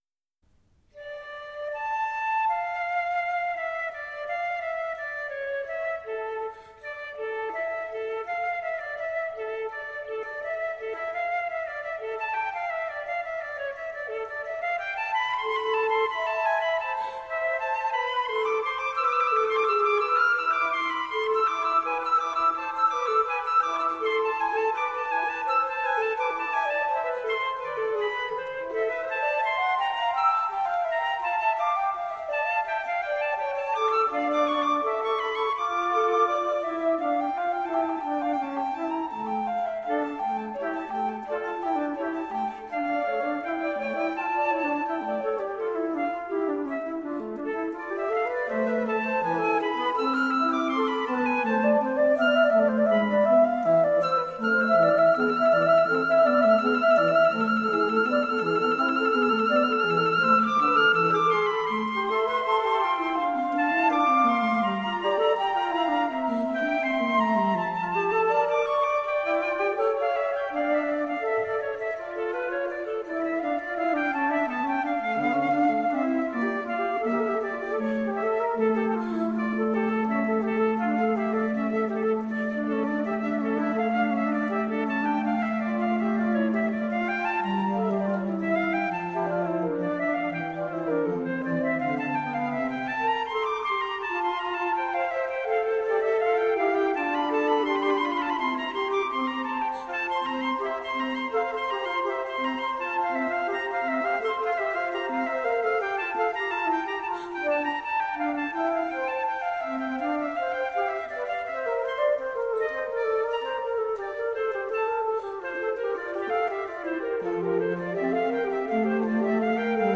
Flautists with finesse, intelligence and grooves.
flute ensemble
recorded live in Hakodate City Art Hall on 22 March 1999
Classical, Baroque, Impressionism, Instrumental
Flute